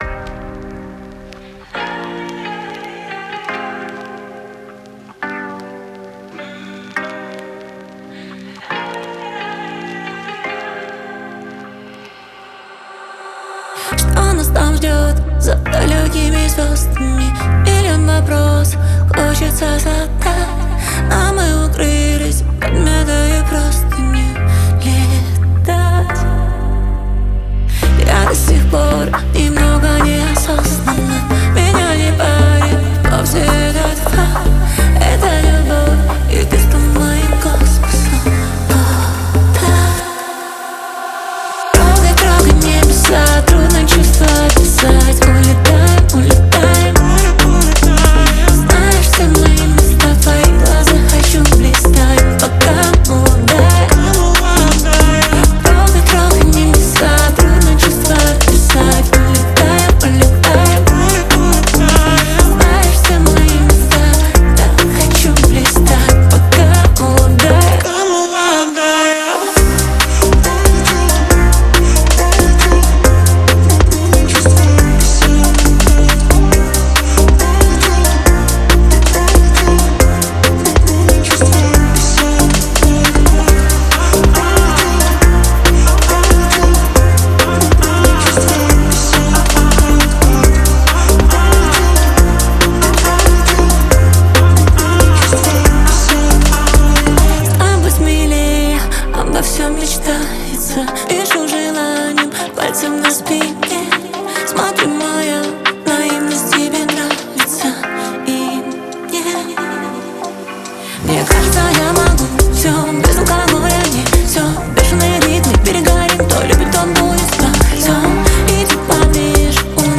• Жанр: Украинская